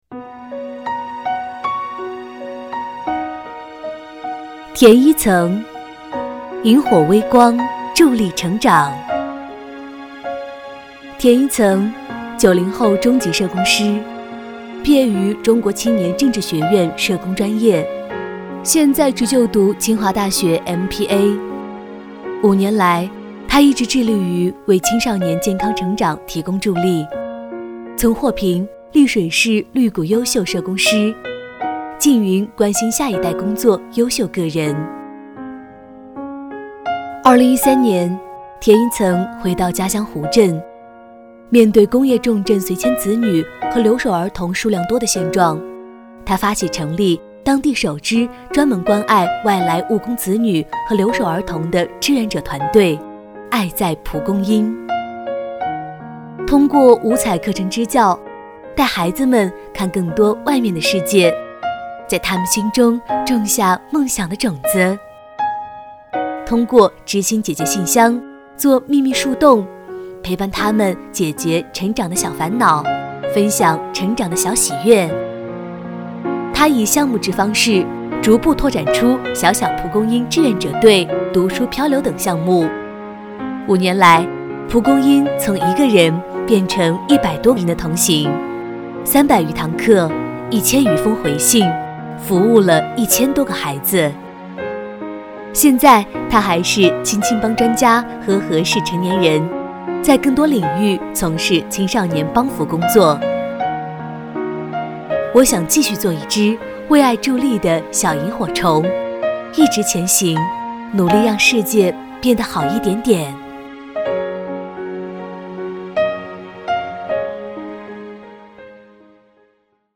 女国语129